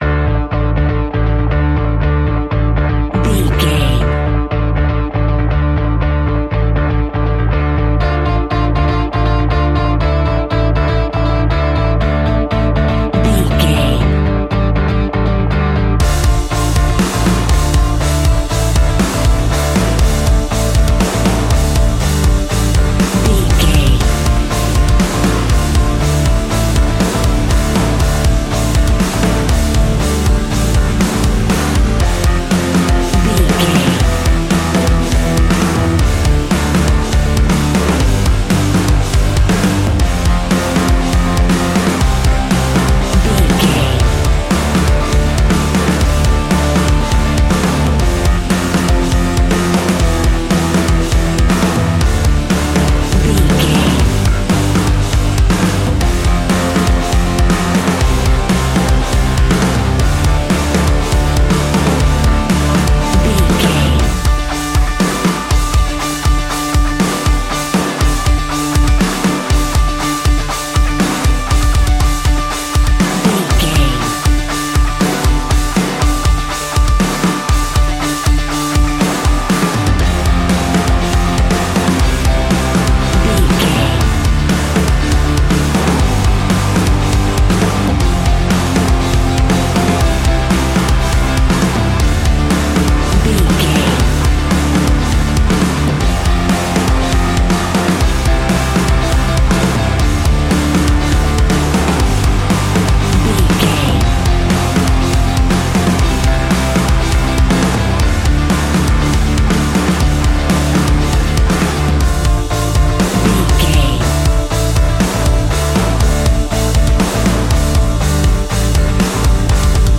Ionian/Major
D♭
hard rock
guitars
heavy metal
instrumentals